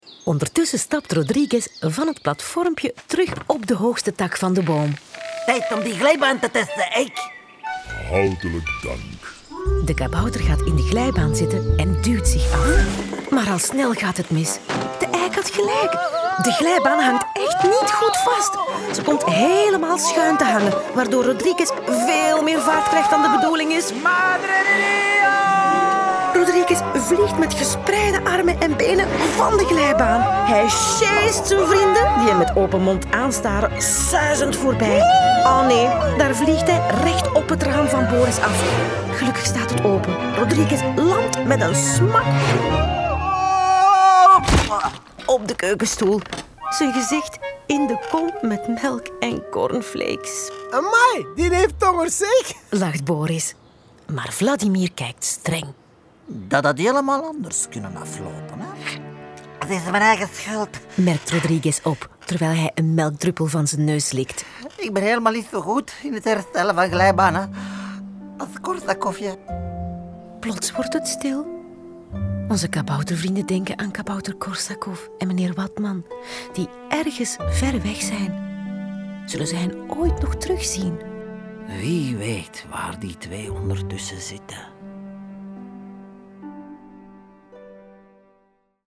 Kabouter Korsakov is een reeks luisterverhalen voor avonturiers vanaf 4 jaar.